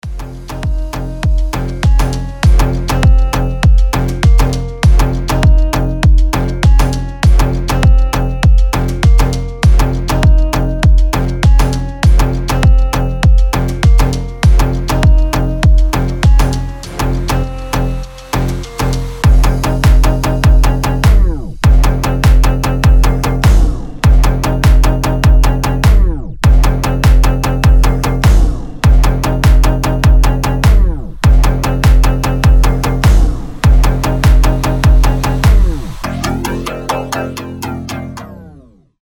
• Качество: 320, Stereo
deep house
Electronic
EDM
без слов
басы
Midtempo
Простой мелодичный мотив